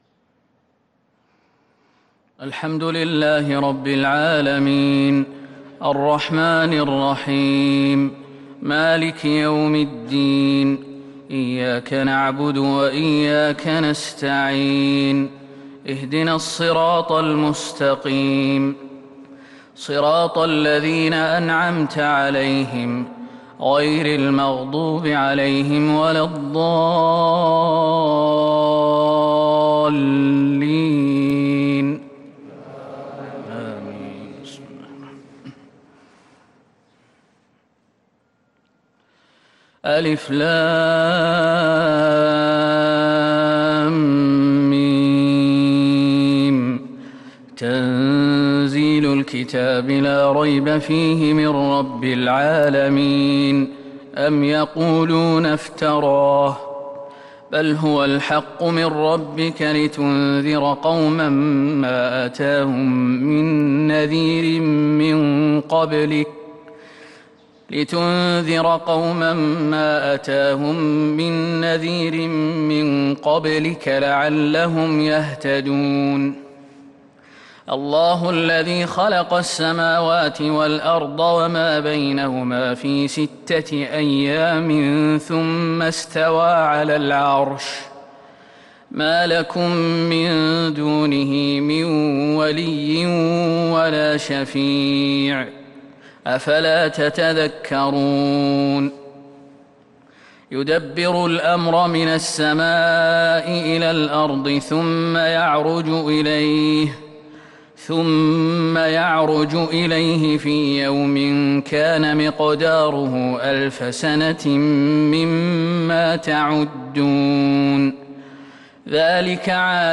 صلاة الفجر للقارئ ياسر الدوسري 23 ربيع الأول 1443 هـ
تِلَاوَات الْحَرَمَيْن .